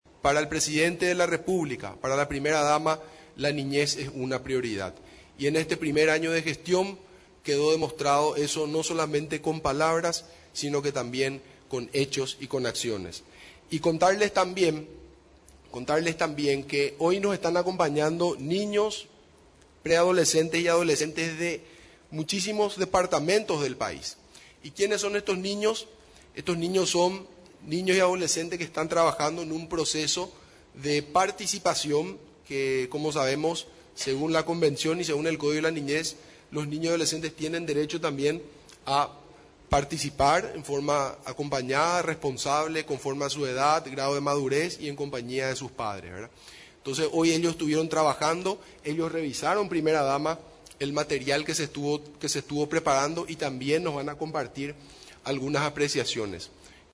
En el informe de Gestión, realizado en la Residencia Presidencial de Mburivichá Róga, estuvo presente en representación del Ejecutivo, la Primera Dama de la Nación, Leticia Ocampos, además de otras autoridades nacionales.